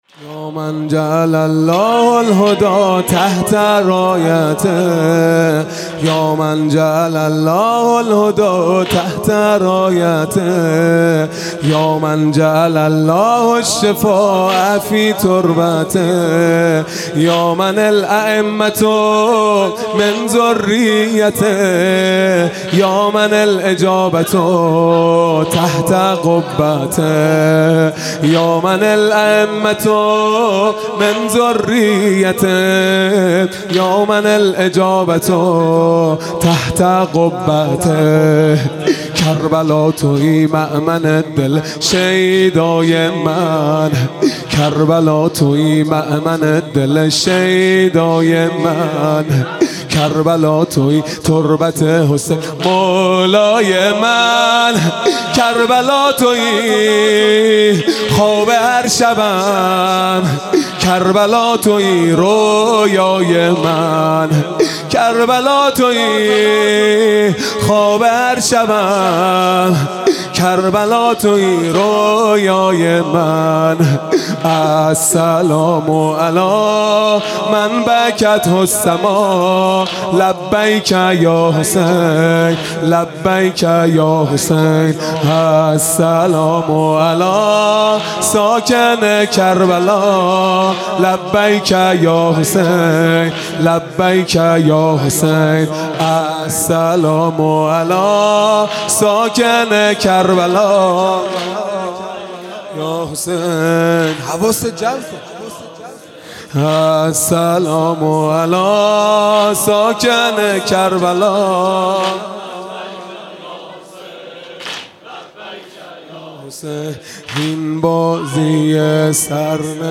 خیمه گاه - هیئت بچه های فاطمه (س) - واحد | یا من جعل الله
محرم 1441 | شب دوم